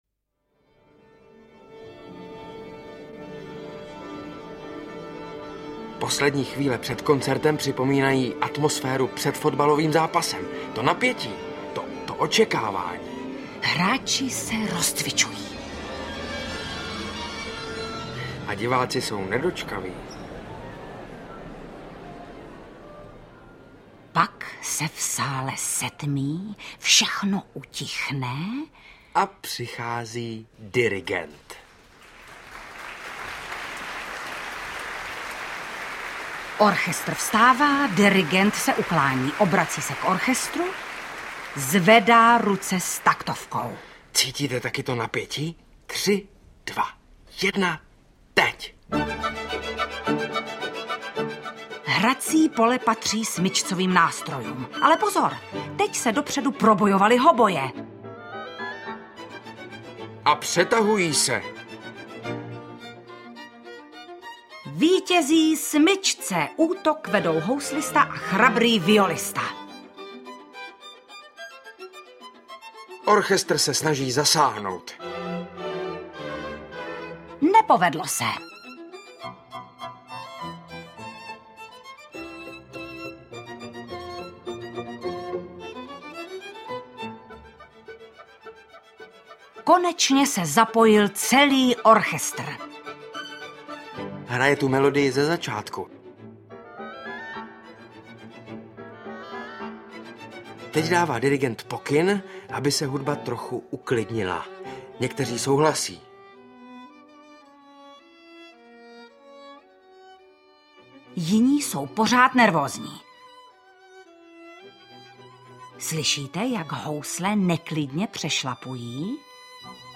Nebojte se klasiky 4 - Bohuslav Martinů audiokniha
Svět vážné hudby otevírají Vanda Hybnerová a Saša Rašilov v hudební škole pro žáky, učitele i zvídavé rodiče. Díky jejich poutavému vyprávění a ukázkám z díla Bohuslava Martinů se mnohému naučíte a poznáte, jak zábavné je být žákem hudební školy.
Ukázka z knihy
• InterpretSaša Rašilov mladší, Vanda Hybnerová